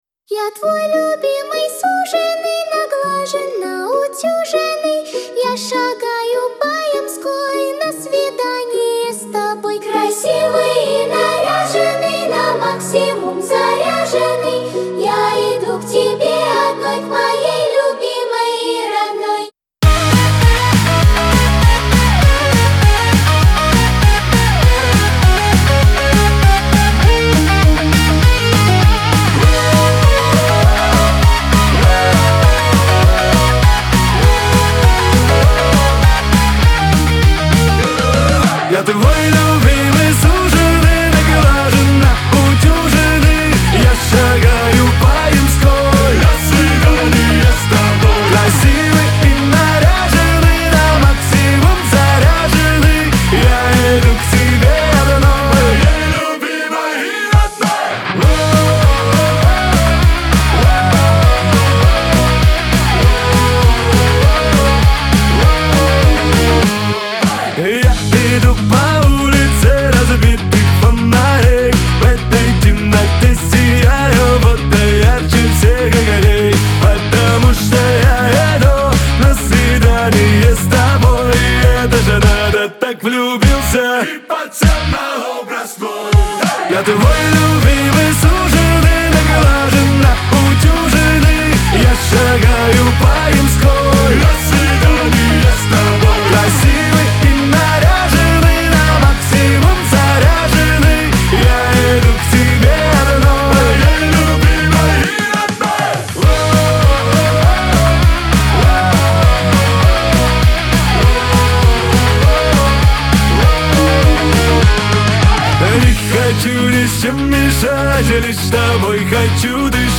dance , Веселая музыка
танцевальная музыка